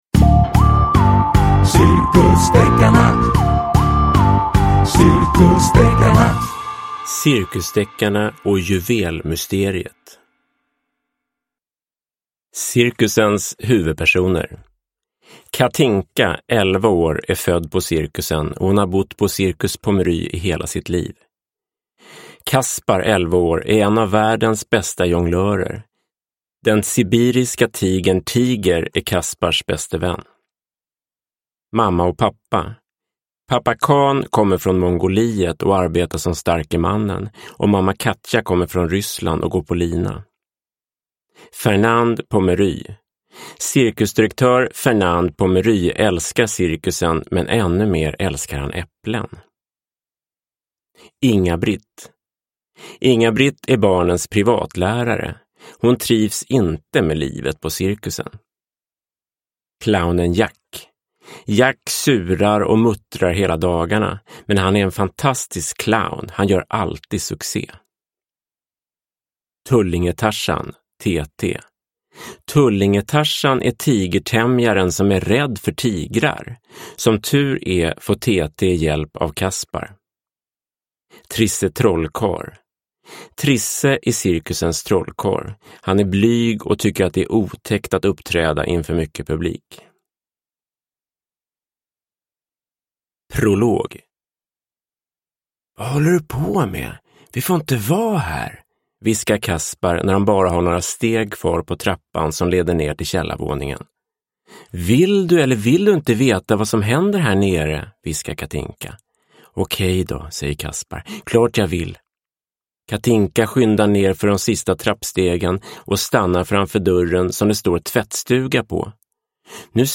Cirkusdeckarna och juvelmysteriet – Ljudbok – Laddas ner